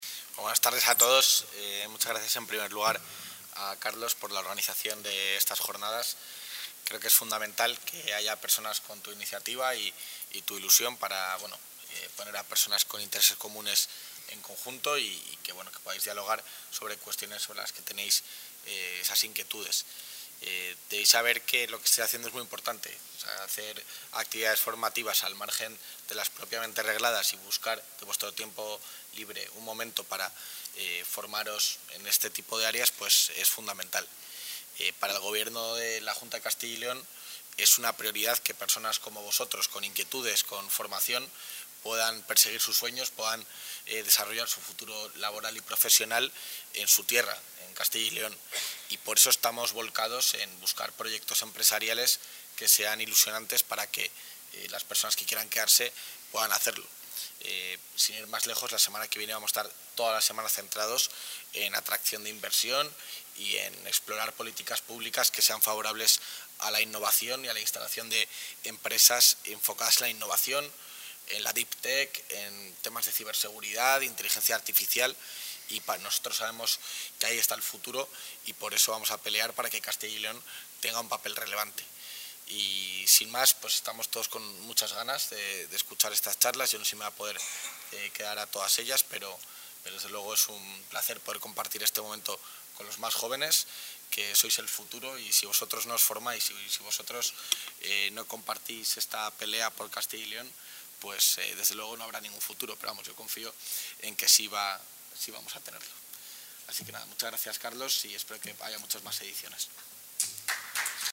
Intervención del vicepresidente de la Junta.
Así lo ha defendido durante la inauguración de una jornada organizada por la Asociación Virus Matemático en la Facultad de Derecho de Valladolid